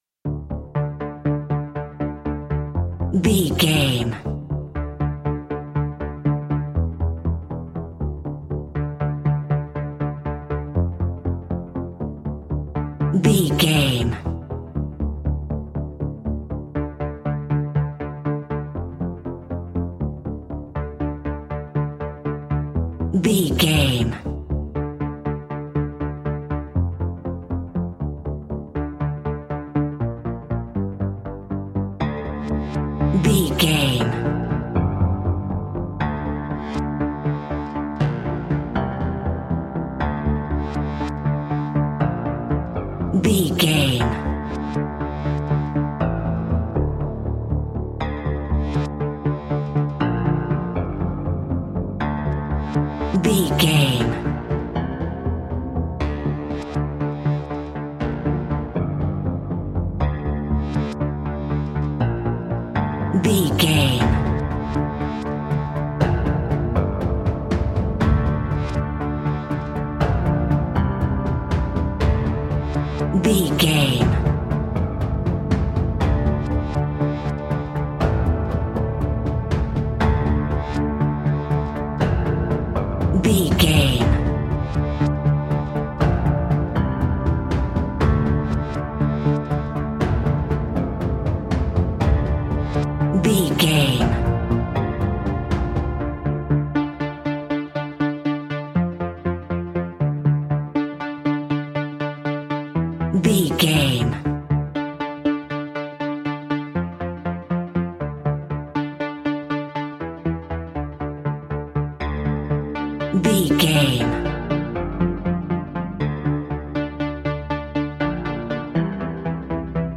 Modern Progressive Suspense Alt.
Aeolian/Minor
D
ominous
dark
eerie
drums
synthesiser
instrumentals
horror music